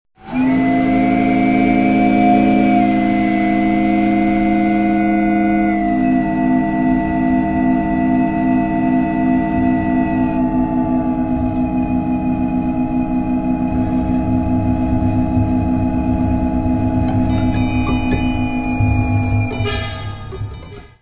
barrel organ
piano, prepared piano
tuba, serpent
double bass
percussion
Music Category/Genre:  New Musical Tendencies